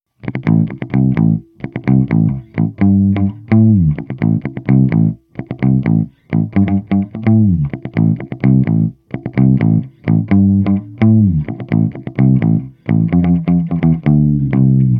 无伴奏低音提琴循环 平滑的平民开始关闭节拍
Tag: 128 bpm Disco Loops Bass Guitar Loops 2.52 MB wav Key : A